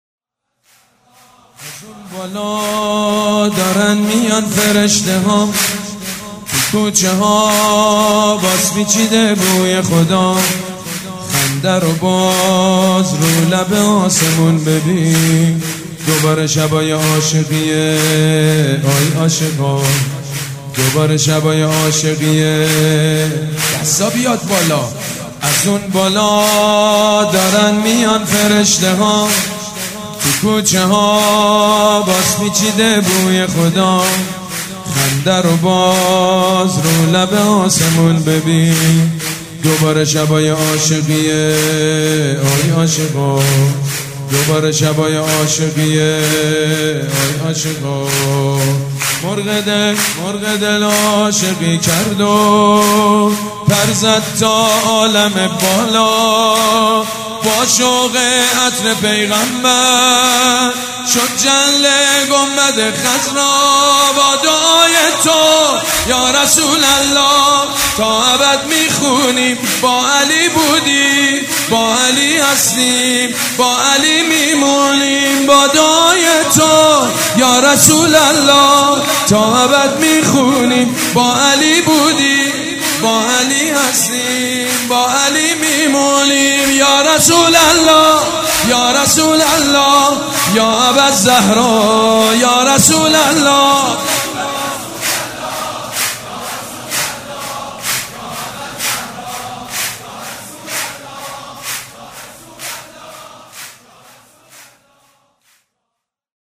دانلود مولودی پیامبر اکرم (ص) و امام جعفر صادق (ع) سید مجید بنی فاطمه